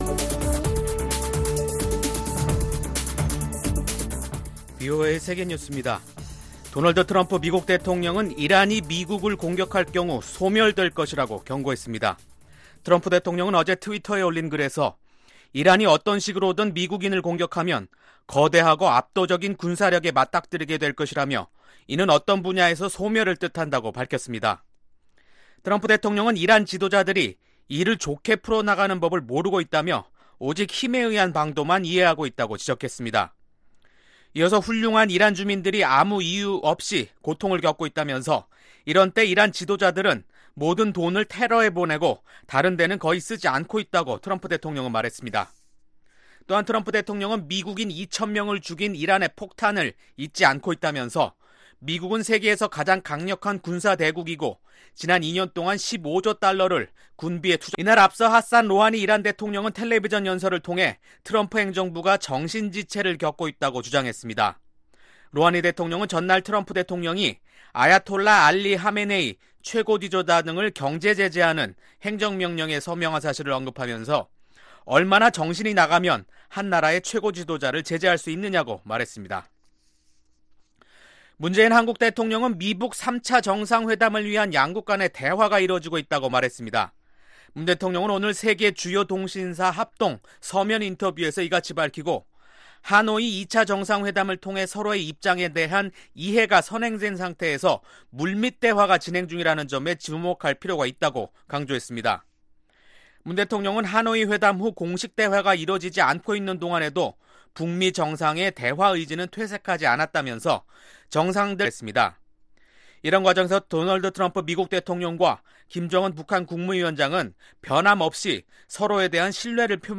VOA 한국어 간판 뉴스 프로그램 '뉴스 투데이', 2019년 6월 26일 2부 방송입니다. 미국과 북한이 3차 정상회담을 위한 대화를 진행 중이라고 문재인 한국 대통령이 밝혔습니다. 북한은 현재 ‘숨겨진 굶주림’의 위기에 직면해 있다고 현지 식량 수급 실태를 조사한 유엔 조사단원이 밝혔습니다.